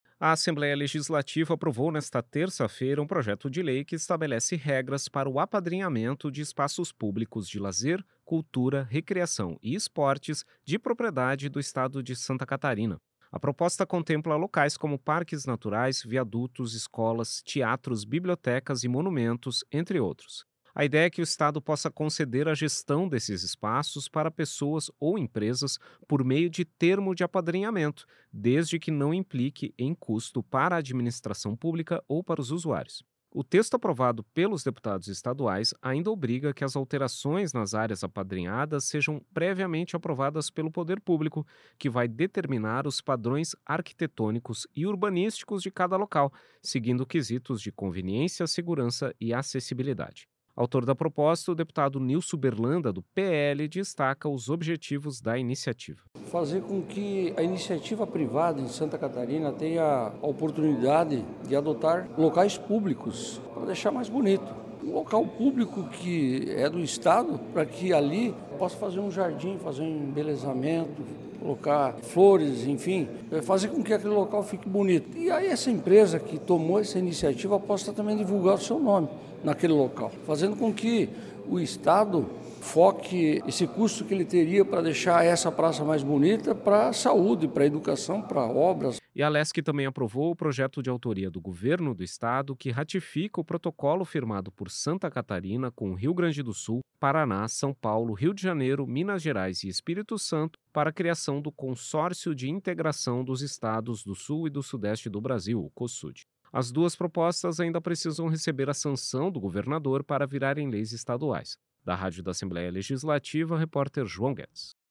Entrevista com:
- deputado Nilso Berlanda (PL), autor do projeto de lei de apadrinhamento de espaços públicos.